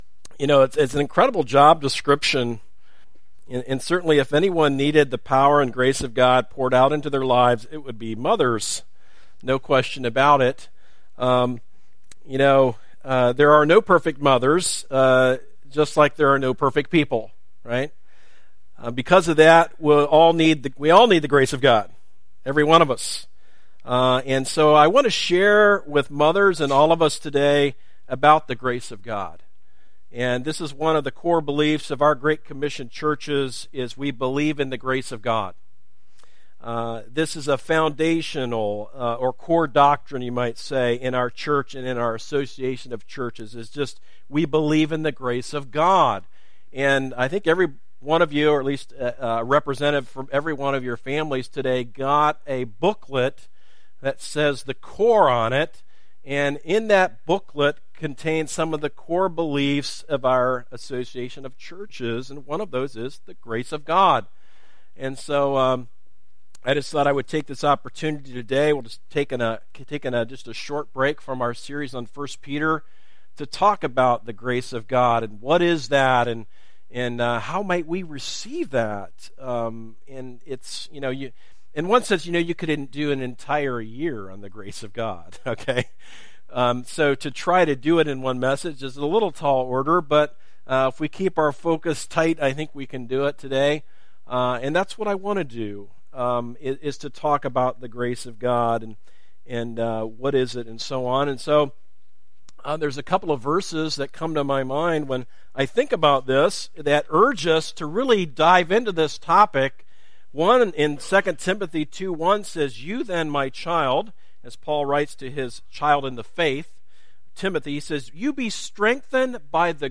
Sermons - series archive - Darby Creek Church - Galloway, OH